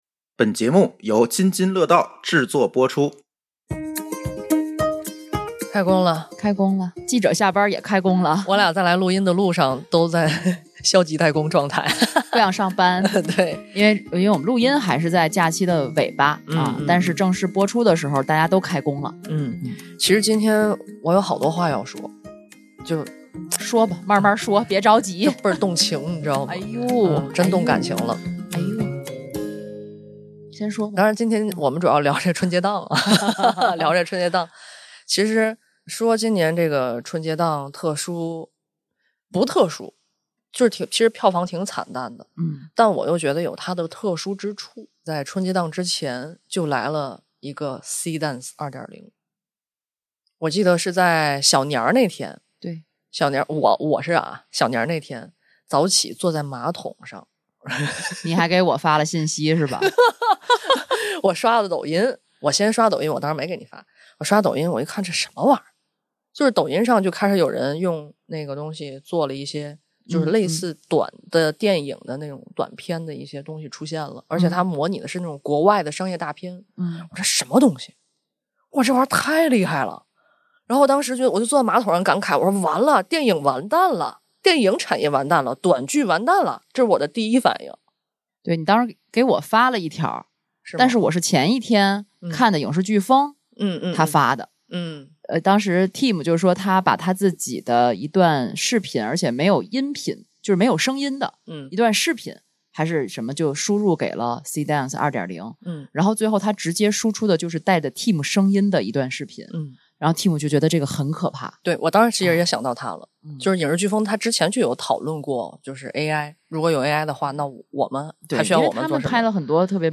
场地支持 / 声湃轩天津录音间